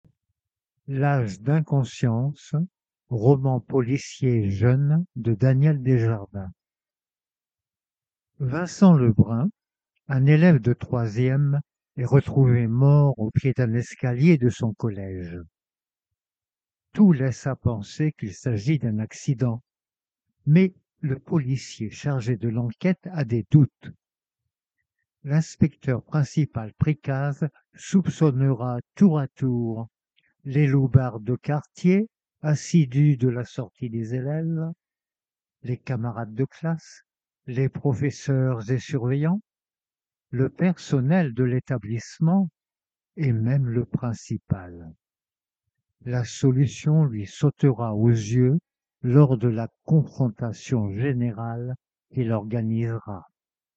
Roman audio pour mal-voyants.